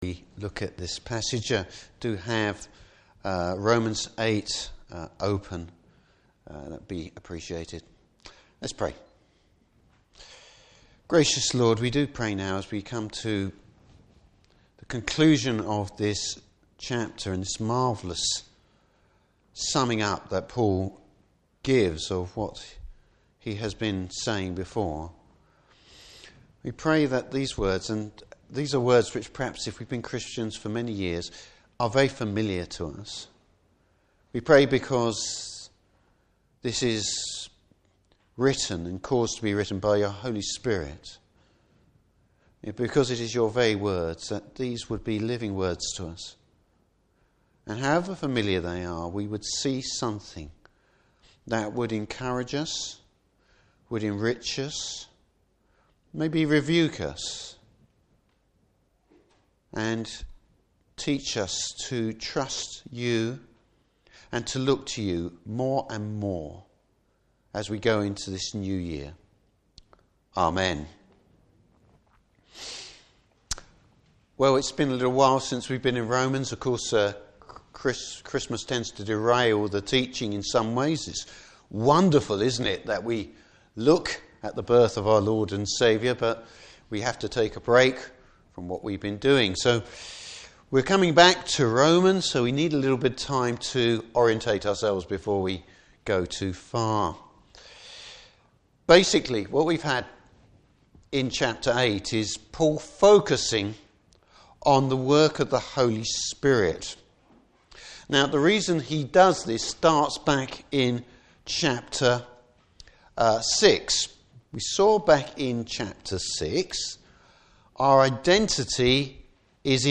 , New Years Sermon.
Service Type: Morning Service God’s initiative and investment in the believer.